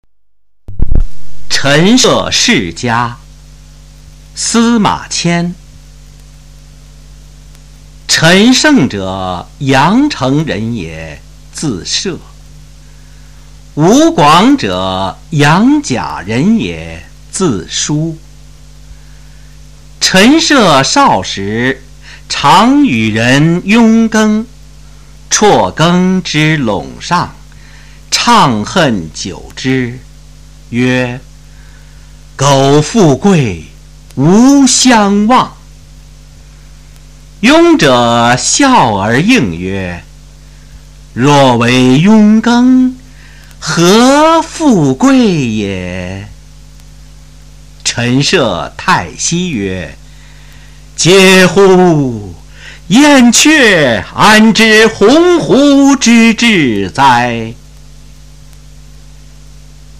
九年级语文下册 22《陈涉世家》男声高清朗读（音频素材）